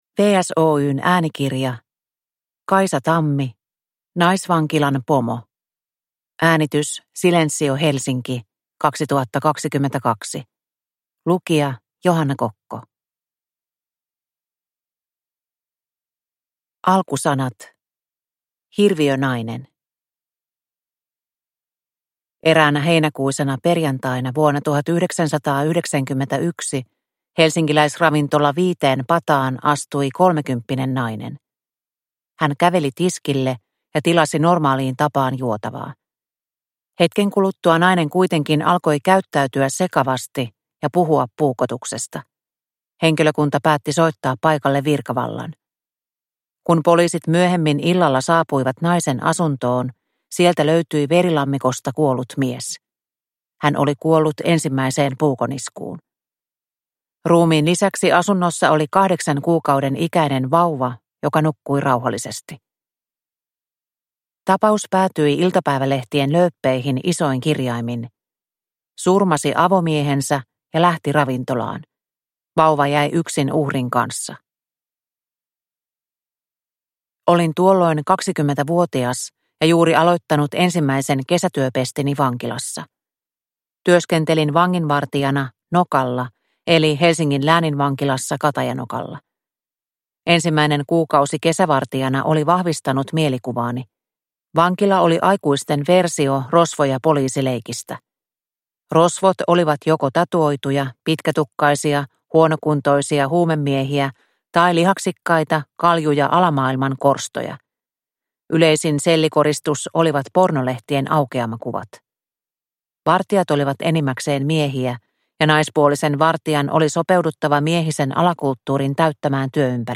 Naisvankilan pomo (ljudbok) av Kaisa Tammi | Bokon